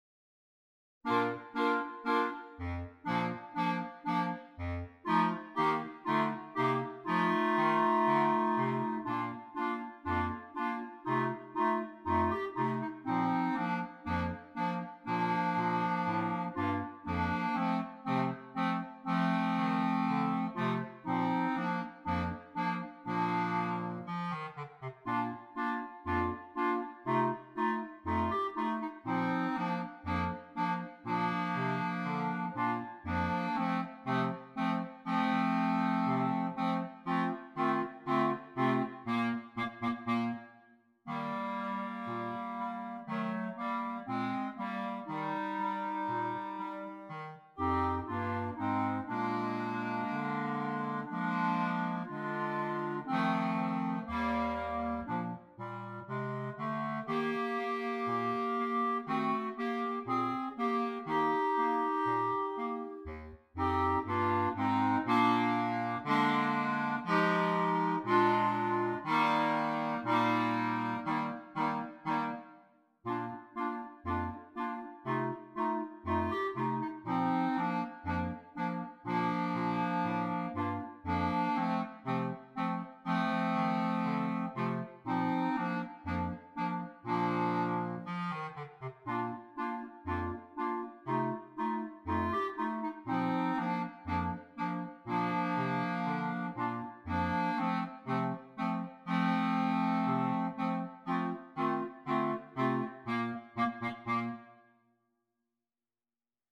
Komponist: Traditionell
Gattung: Für 3 Klarinetten und Bassklarinette
Polka für alle!